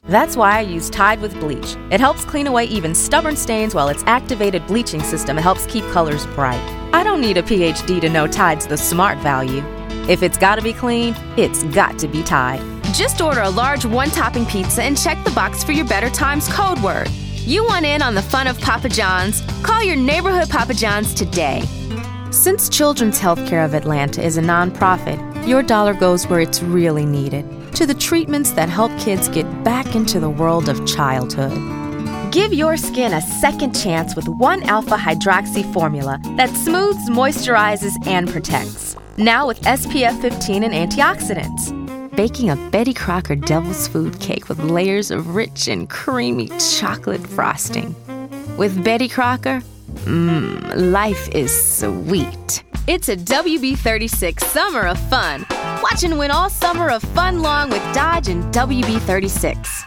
Commercial
Commercial-VO-Demo.mp3